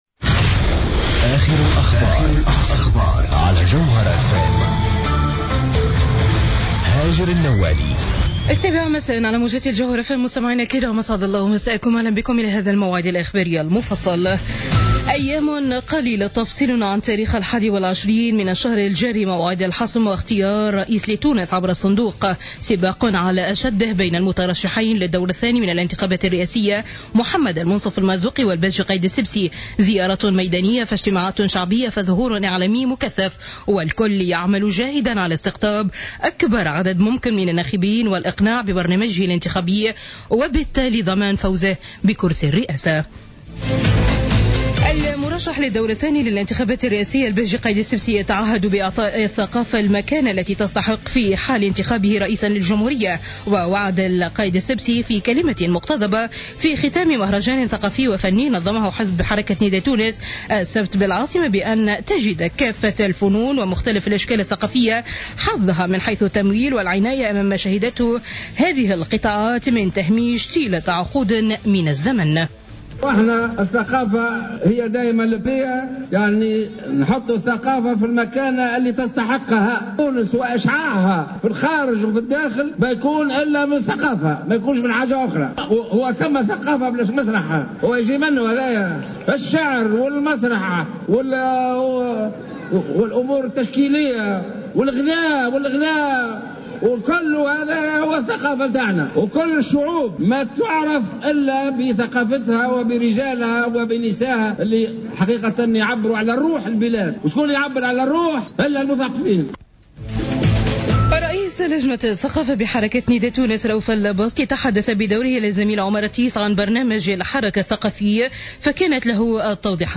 نشرة أخبار السابعة مساء ليوم السبت 13-12-14